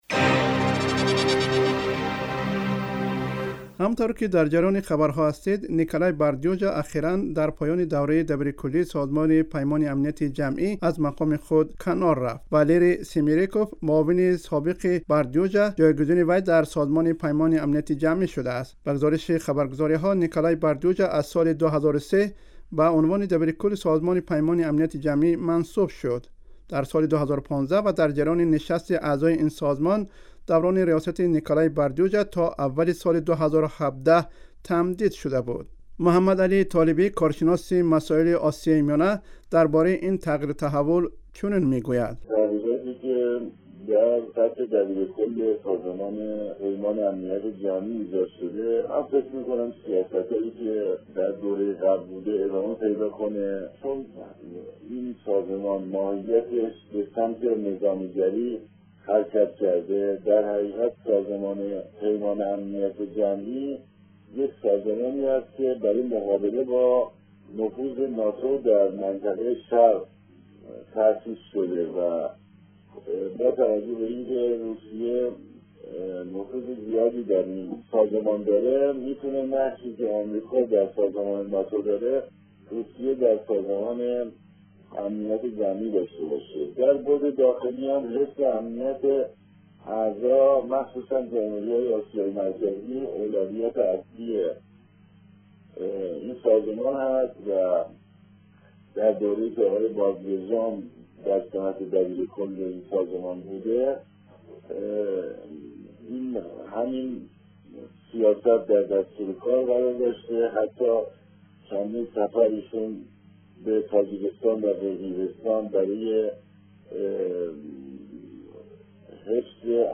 гузориши вижае